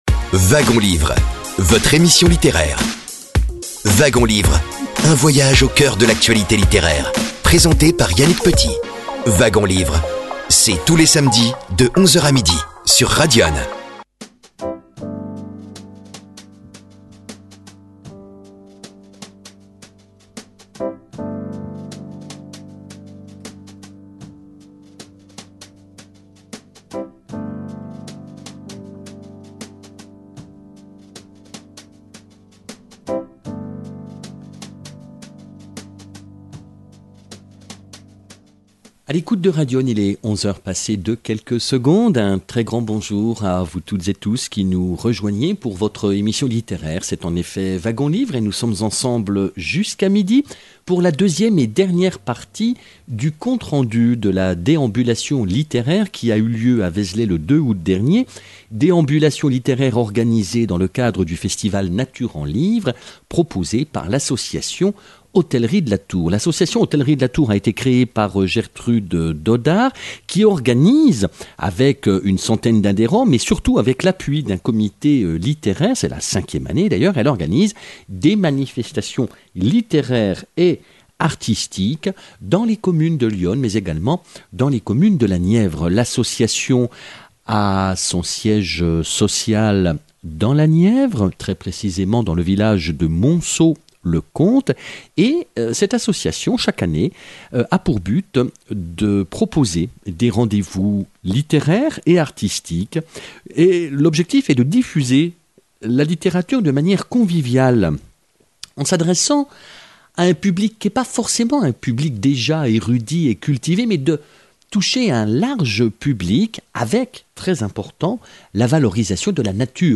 Festival Nature en livres (déambulation littéraire à vézelay). 2e et dernière partie